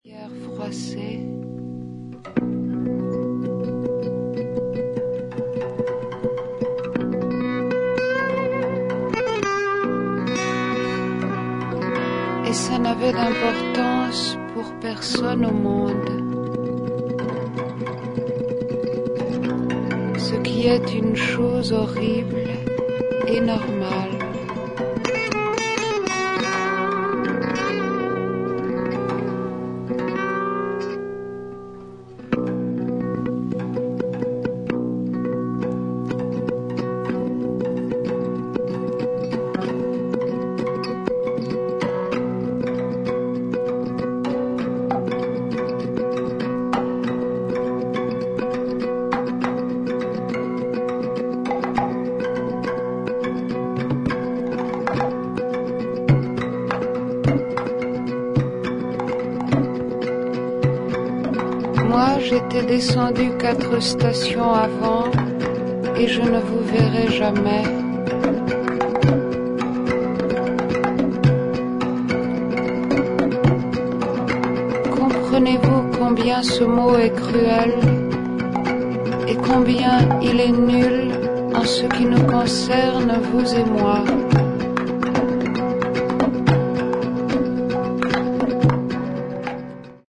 ギター、チェロ、パーカッションによる演奏がインド古典音楽にも通ずる